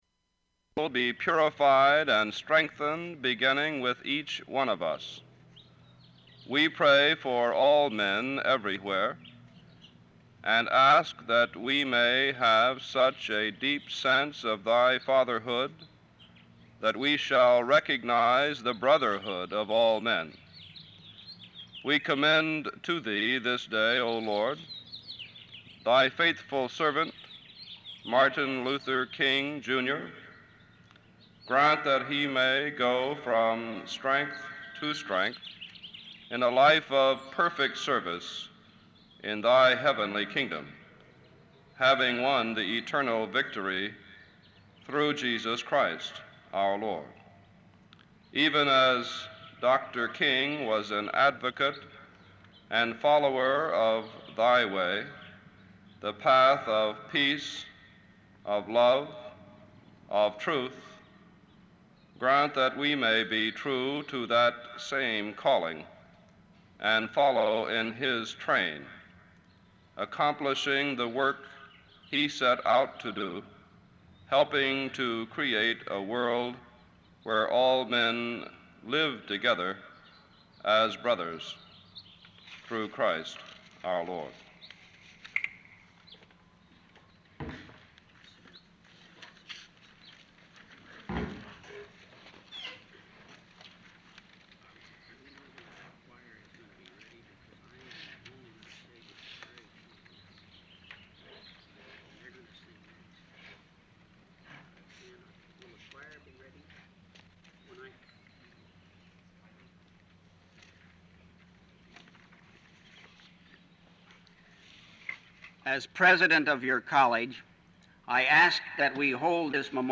Download audio Metrics 34 views 31 downloads Citations: EndNote Zotero Mendeley Audio Cal Poly Memorial service for Dr. Martin Luther King, Jr. On Monday, April 10, 1968, Cal Poly held a memorial service for Dr. Martin Luther King. The memorial was held at the Dexter Lawn and there was an estimated 1,500 students, faculty, and administrators in attendance.
Form of original Open reel audiotape